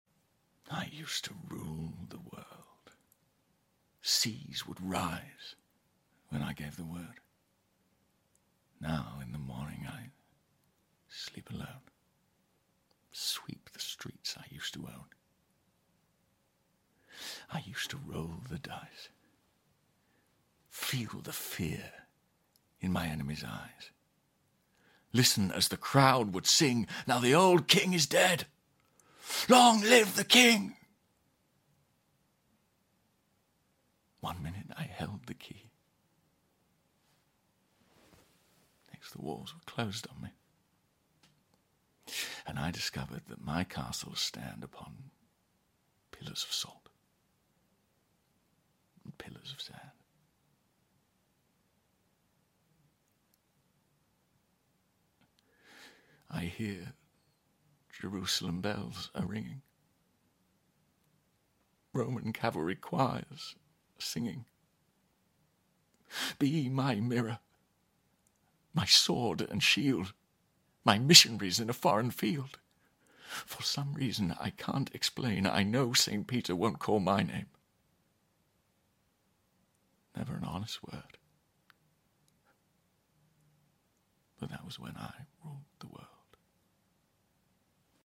🎭 Dramatic Monologue: Viva La sound effects free download